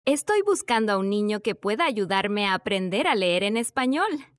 Es decir que con solo escuchar nuestras voces durante 15 segundos, Voice Engine es capaz de generar voces que prácticamente son idénticas a nuestra voz.
Audio generado